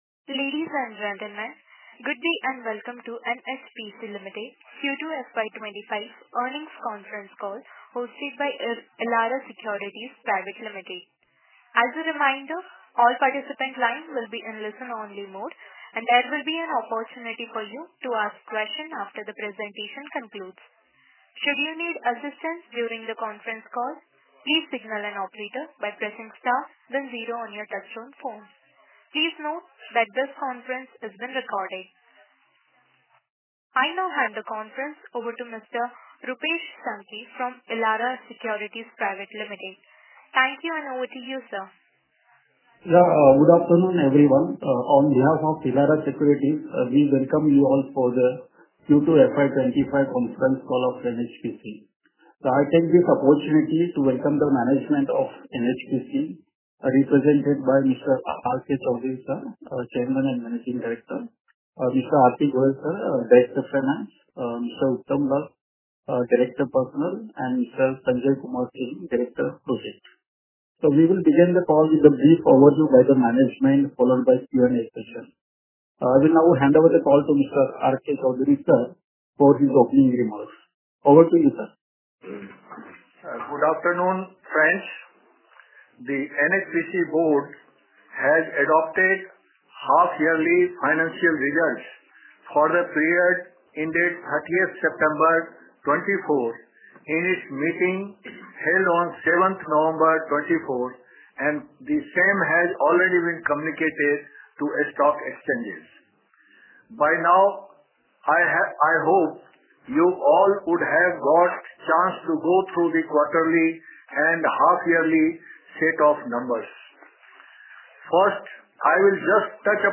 Earning_concall_Q2_FY 25.mp3